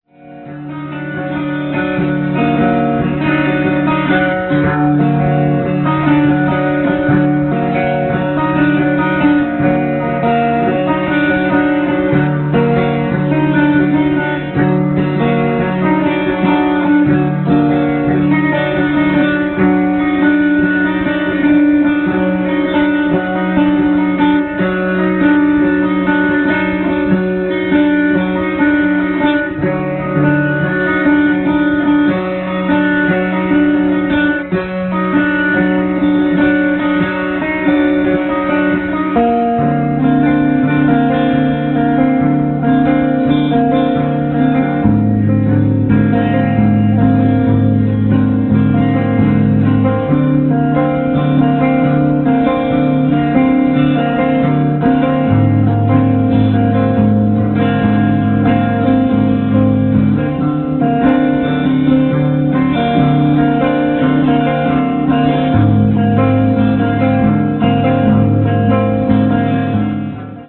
A little collage of song's fragment from band's practices: